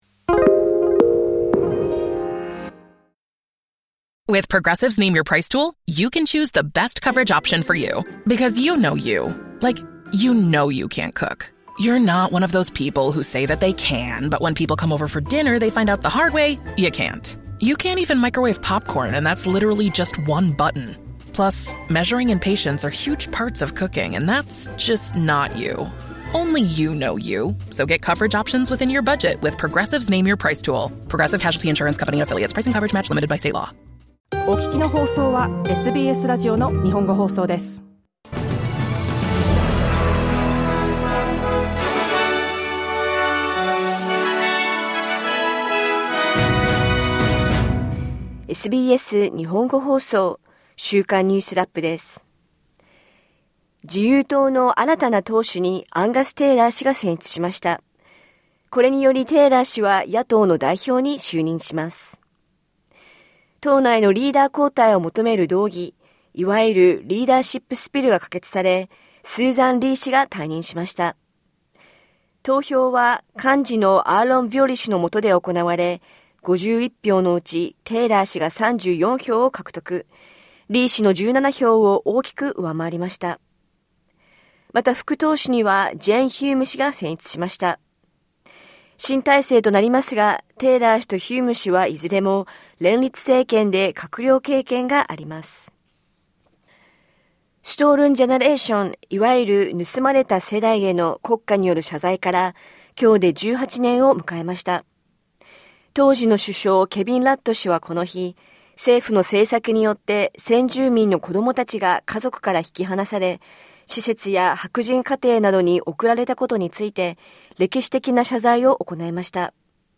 1週間を振り返るニュースラップです。